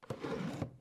DrawerClose.wav